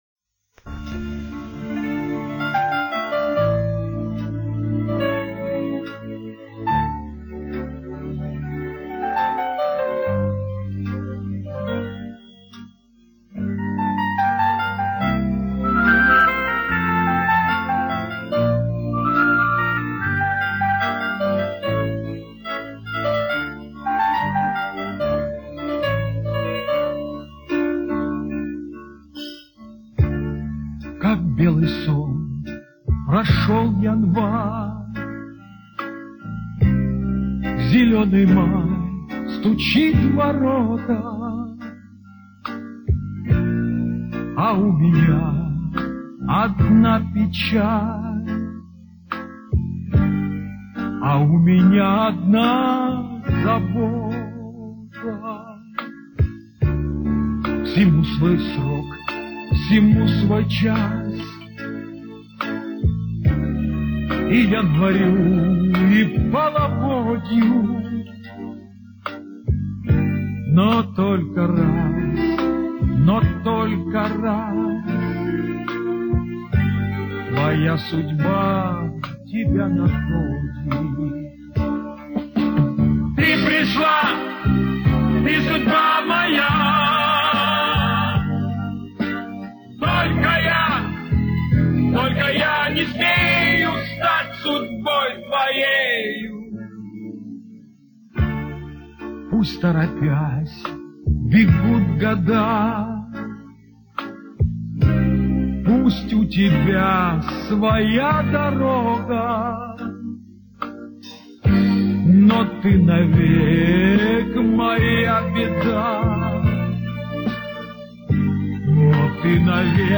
Спасибо, большое, хотя качество не высокое.